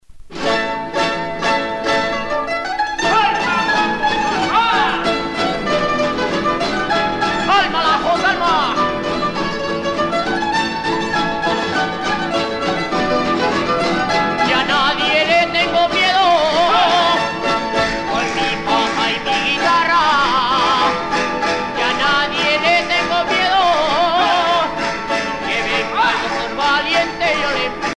danse : séguédille
Pièce musicale éditée